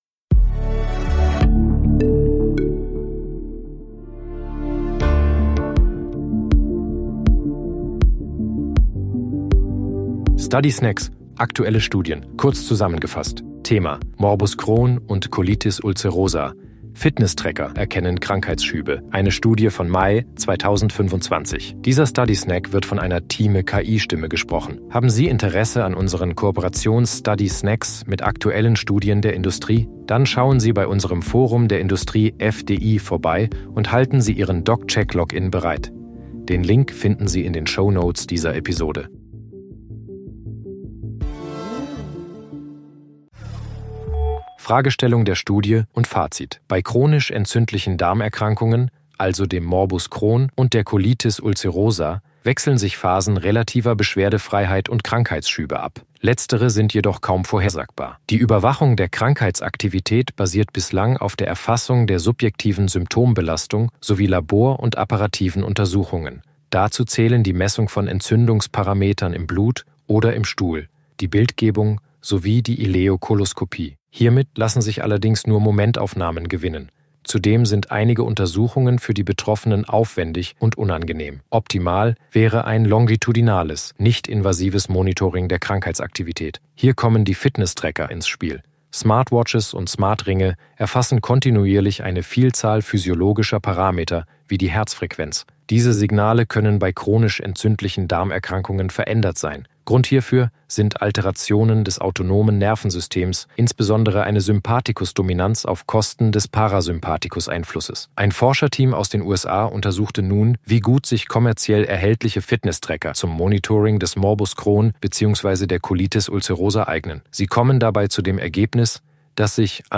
sind mit Hilfe von künstlicher Intelligenz (KI) oder maschineller
Übersetzungstechnologie gesprochene Texte enthalten